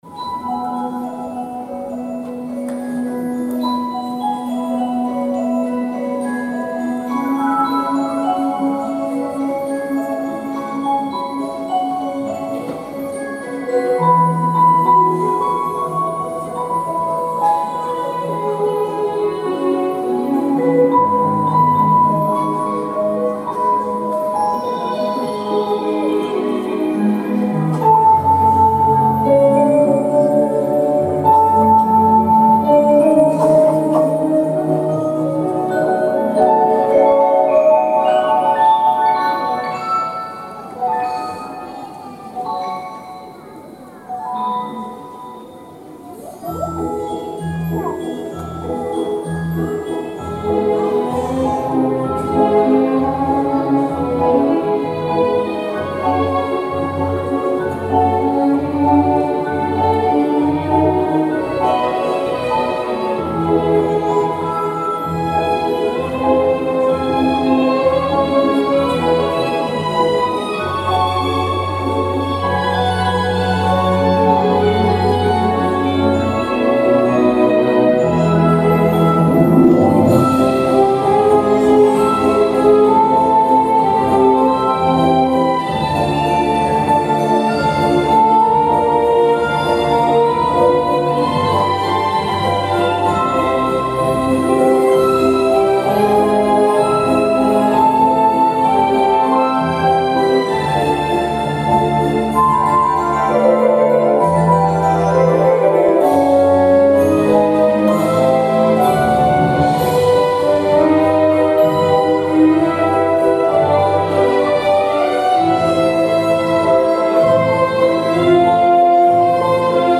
Здесь запись из зала (с концерта), а мне нужно найти чистую студийную фонограмму.